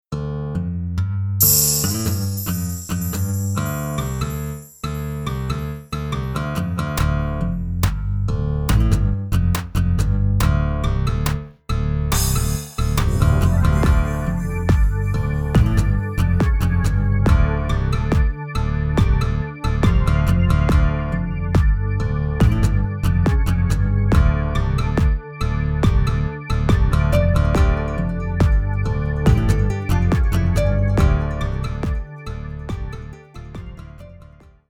ブラッシッングまで
専門的に言うとベロシティの値で一定値越えるとブラッシングやスライドっぽく鳴る模様。
けっこうそれっぽく聞こえると思うんだけどな。